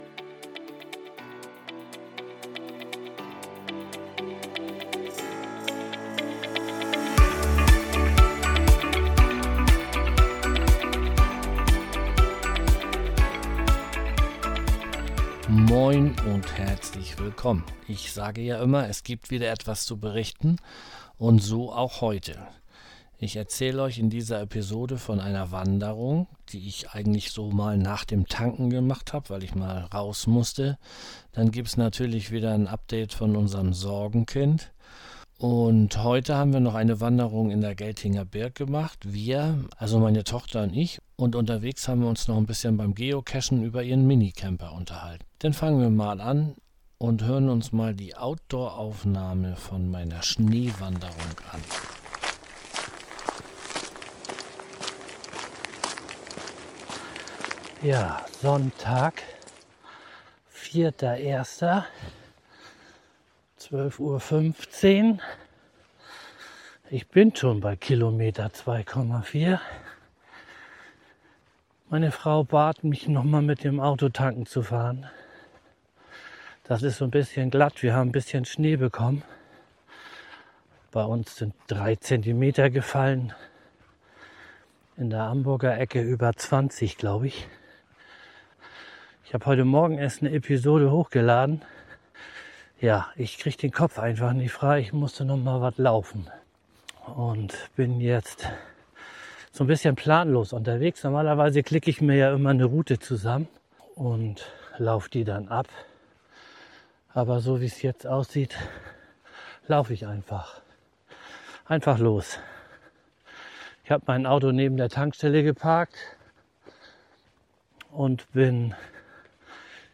Beschreibung vor 2 Monaten In dieser Episode nehme ich Euch auf 2 Wanderungen mit. Letztere führt beim Geocaching durch die Geltinger Birk.